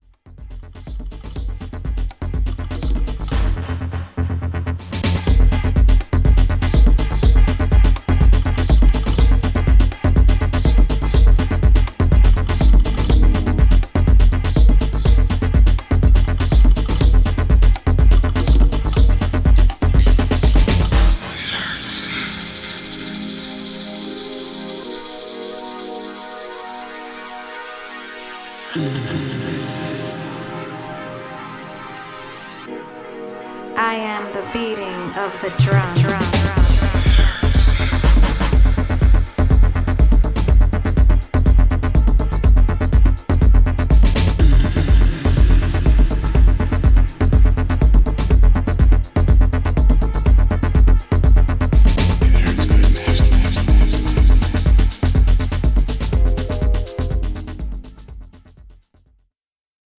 ホーム ｜ HOUSE / TECHNO > HOUSE